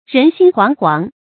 人心皇皇 注音： ㄖㄣˊ ㄒㄧㄣ ㄏㄨㄤˊ ㄏㄨㄤˊ 讀音讀法： 意思解釋： 見「人心惶惶」。